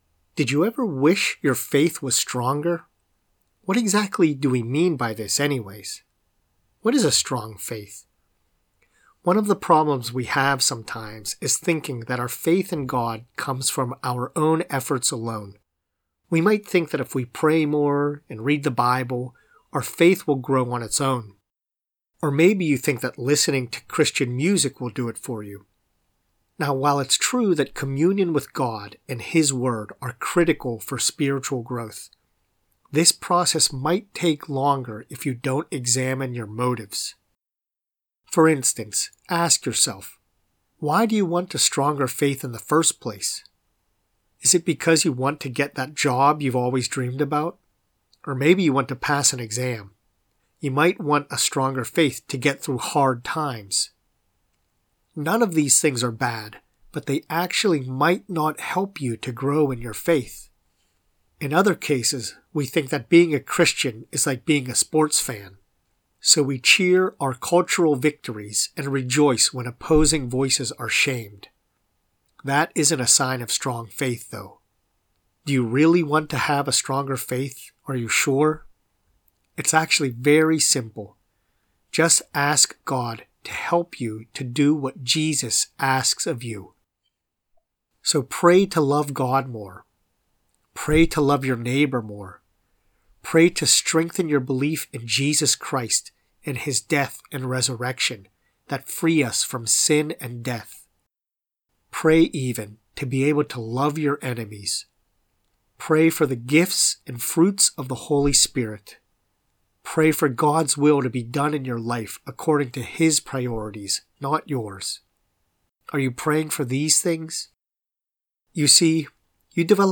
prayer-for-a-stonger-faith.mp3